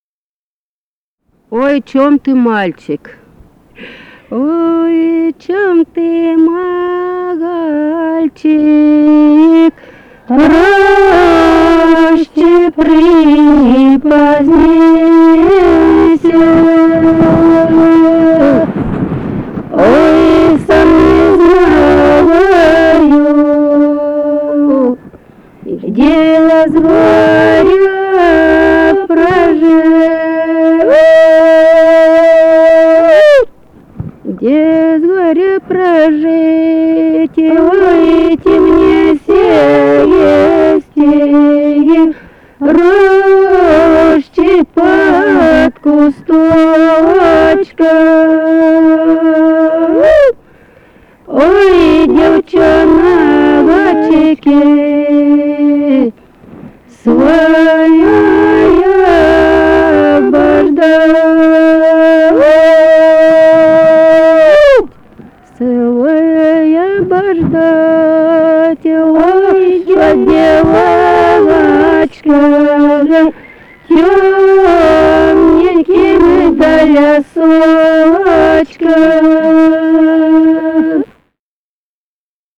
Музыкальный фольклор Климовского района 055. «Ой, чом ты, мальчик» (жнивная).
Записали участники экспедиции